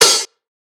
Closed Hats
Live Hat - Antidote.wav